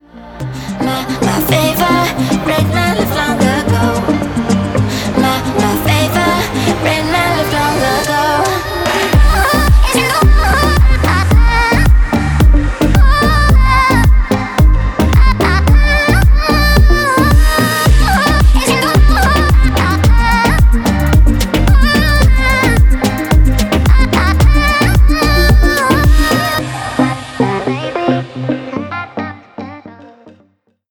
• Качество: 320, Stereo
женский вокал
dance
house